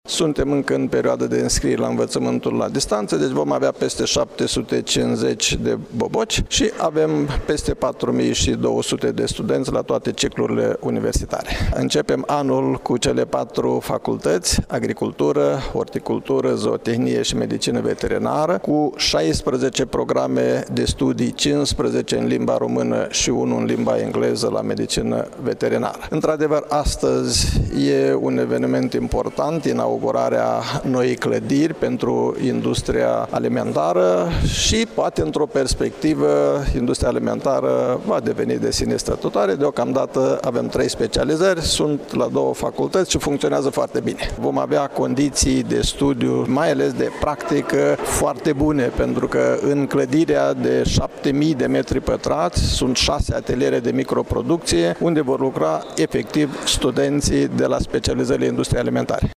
Universitatea de Științe Agricole și Medicină Veterinară „Ion Ionescu de la Brad” din Iași a deschis astăzi anul academic prin inaugurarea unui nou corp de clădire.